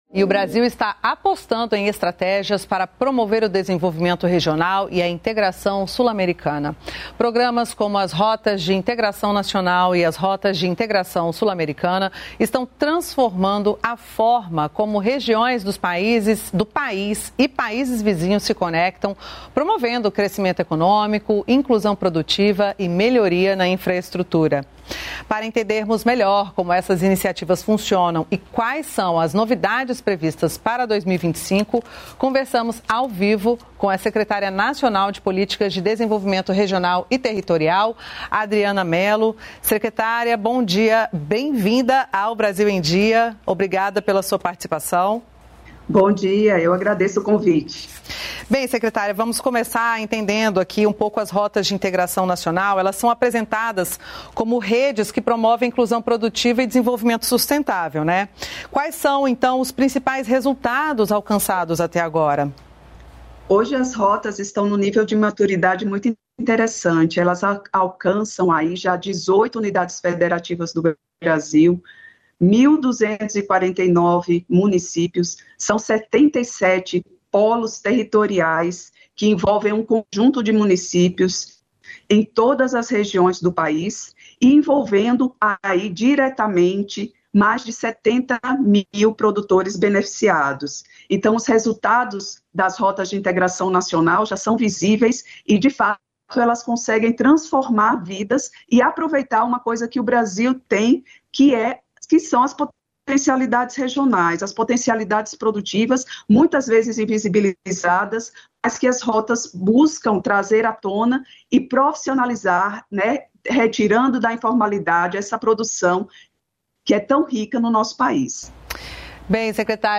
Brasil em Dia - Entrevista
A secretária nacional de Políticas de Desenvolvimento Regional e Territorial, Adriana Melo, explica as iniciativas e quais as novidades previstas para 2025.